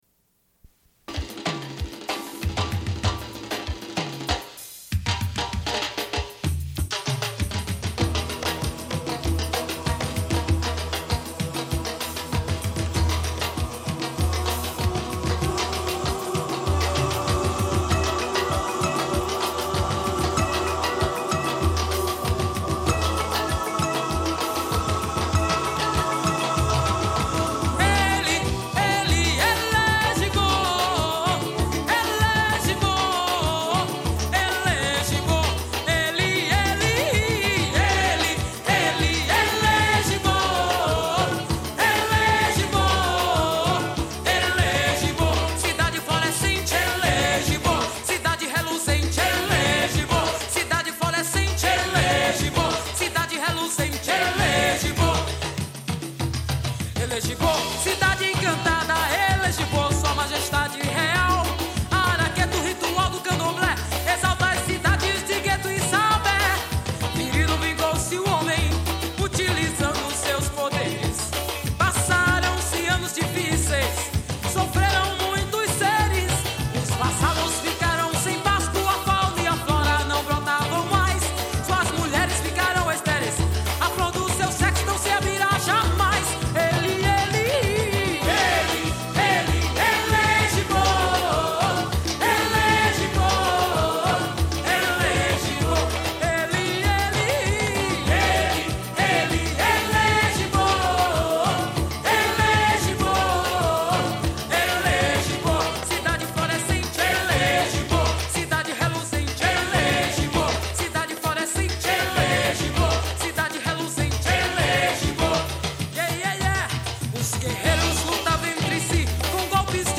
Une cassette audio, face A31:44